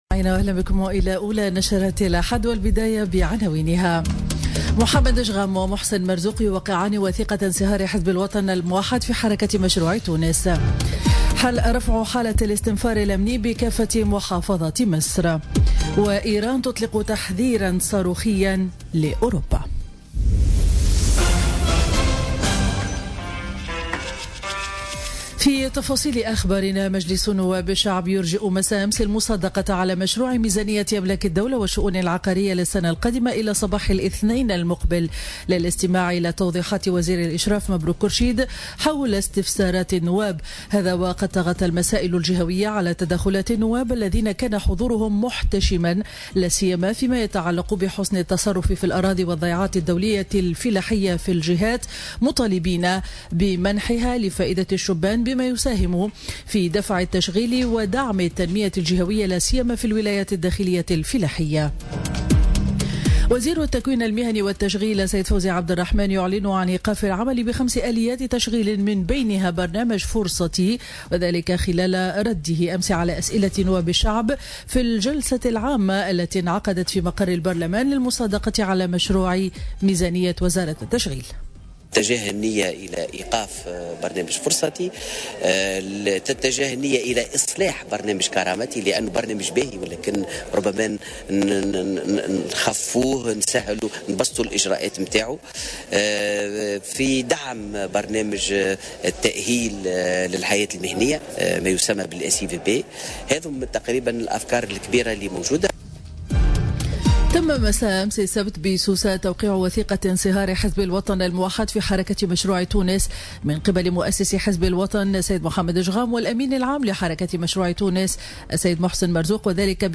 نشرة أخبار السابعة صباحا ليوم الاحد 26 نوفمبر 2017